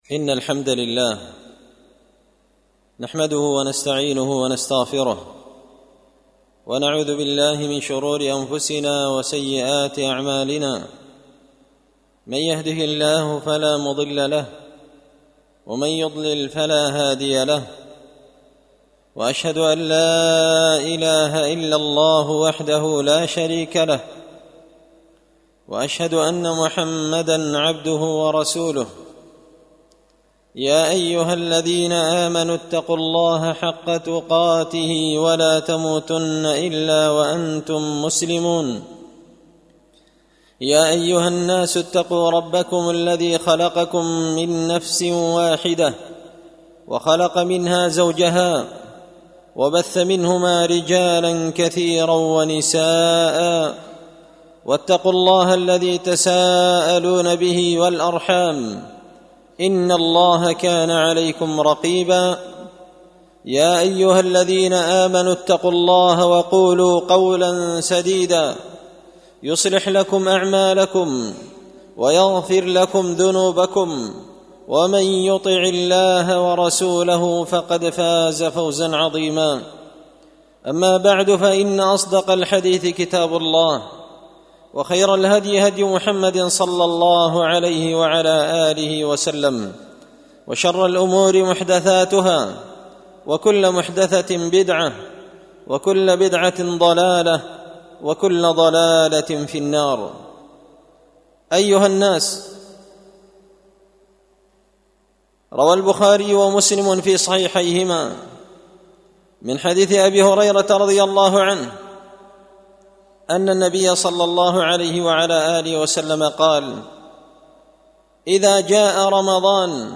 خطبة جمعة بعنوان – استقبال شهر رمضان
دار الحديث بمسجد الفرقان ـ قشن ـ المهرة ـ اليمن